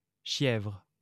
Chièvres (French pronunciation: [ʃjɛvʁ]